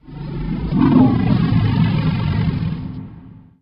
growl2.ogg